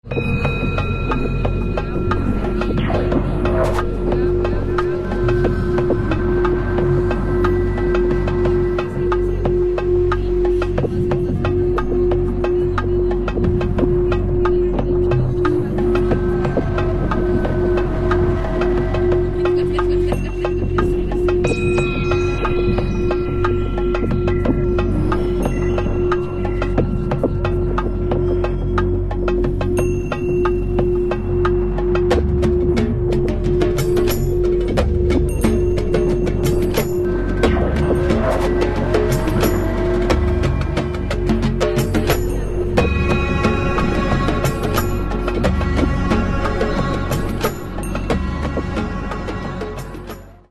Catalogue -> Rock & Alternative -> Electronic Alternative